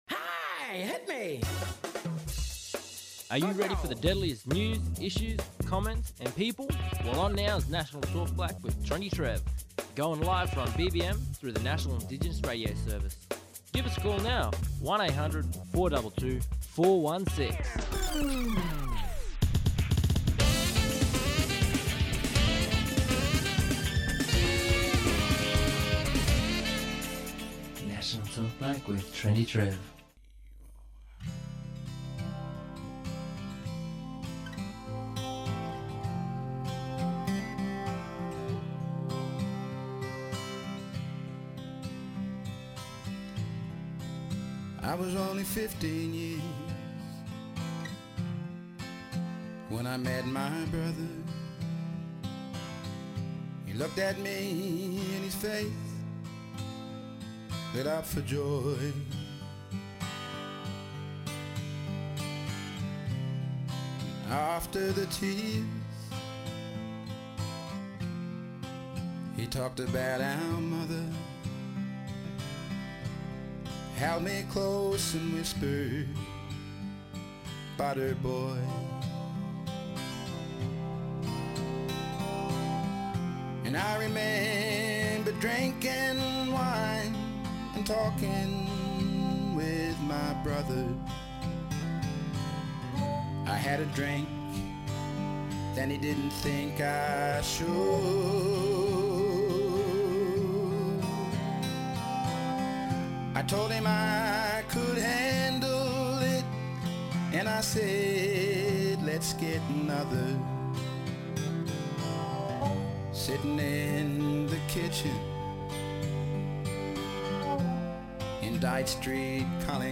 Special Guests Live today from Yarrabah for Gurriny Yealamucka Health Services Aboriginal Corporation for their 2025 NAIDOC Day!